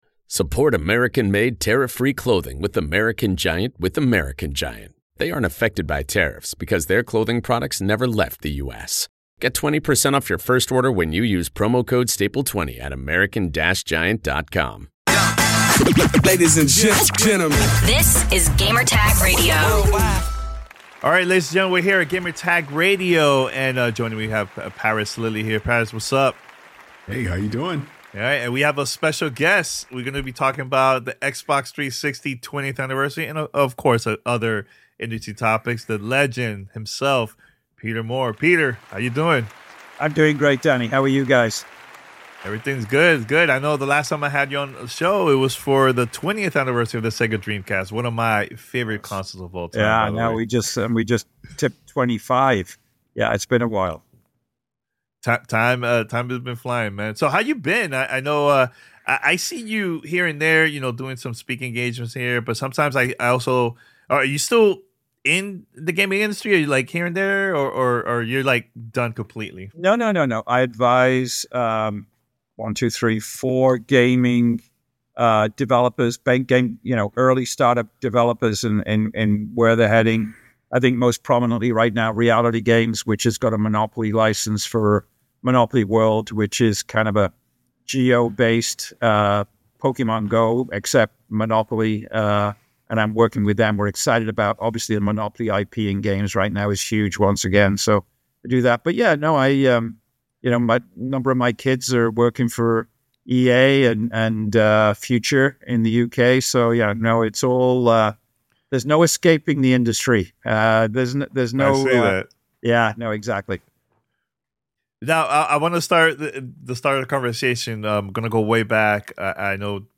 Peter Moore Looks Back: An Interview Celebrating the Xbox 360's 20th Anniversary